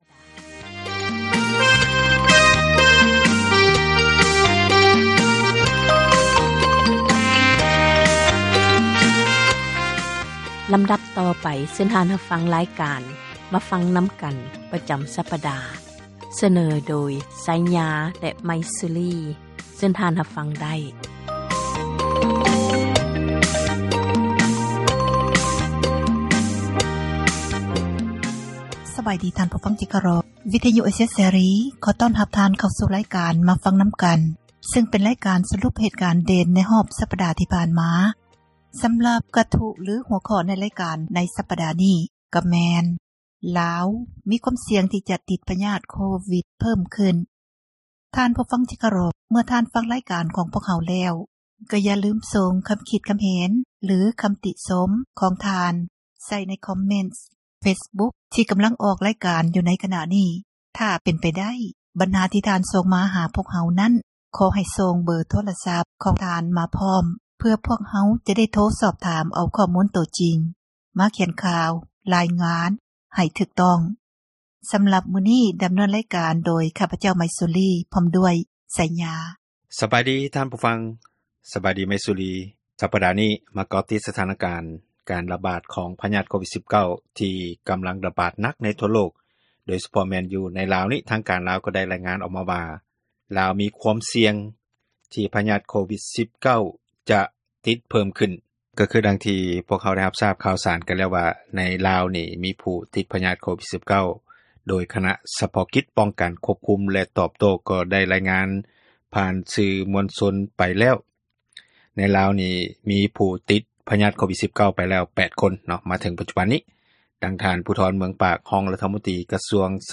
"ມາຟັງນຳກັນ" ແມ່ນຣາຍການສົນທະນາ ບັນຫາສັງຄົມ